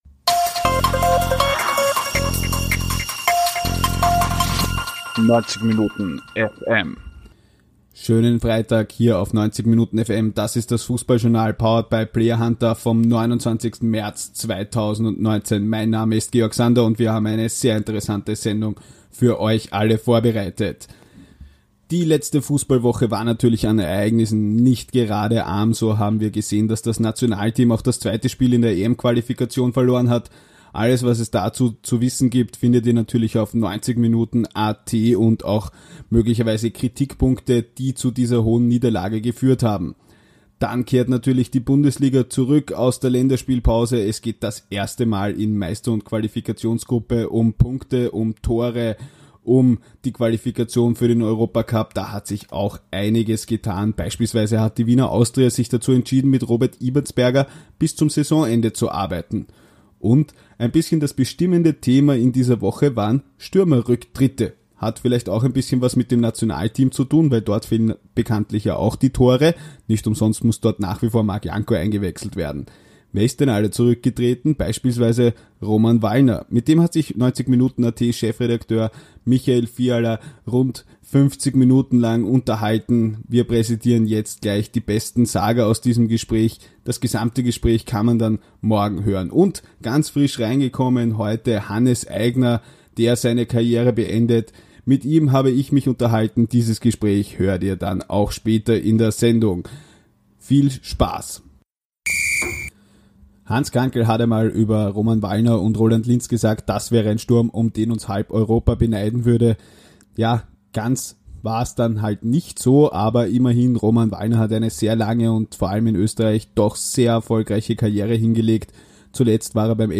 1 Roman Wallner im Interview